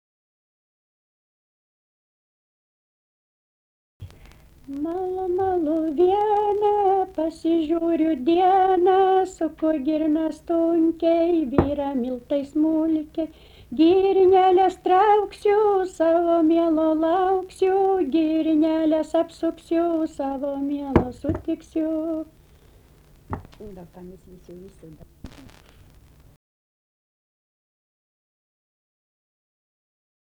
daina, kalendorinių apeigų ir darbo
Rėkučiai
vokalinis